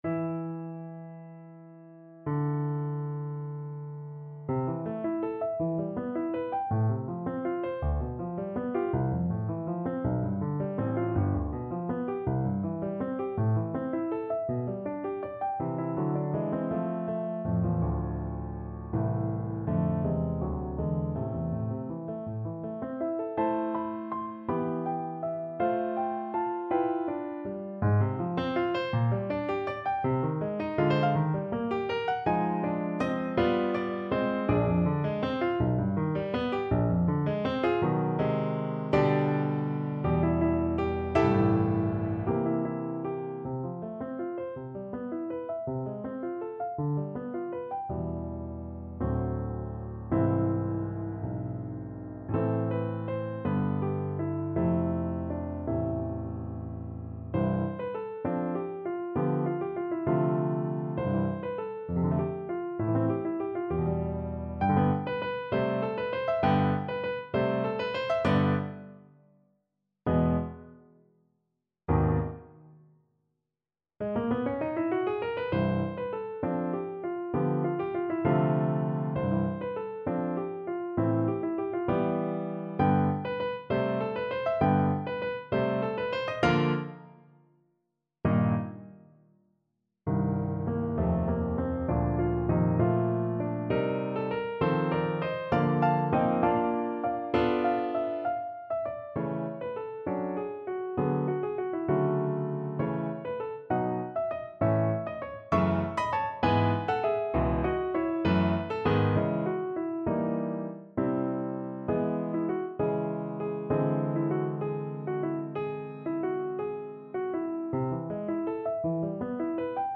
6/8 (View more 6/8 Music)
Classical (View more Classical Violin Music)